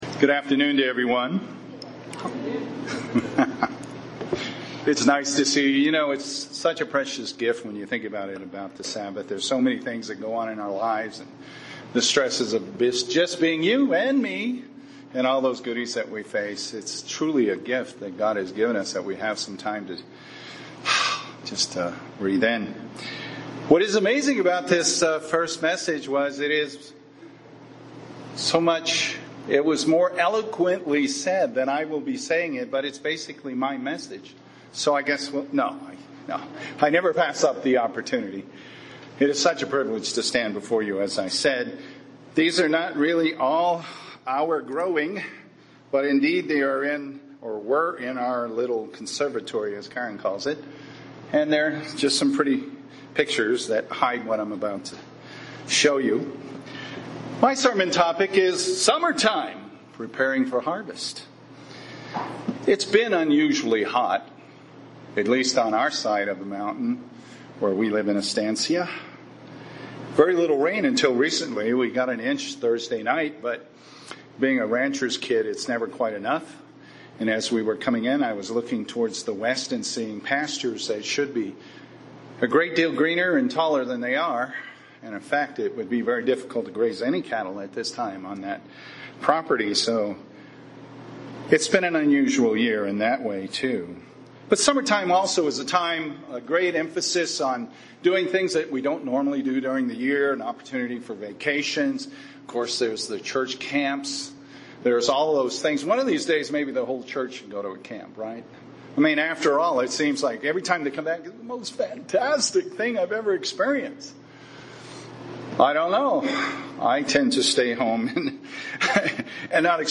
Given in Albuquerque, NM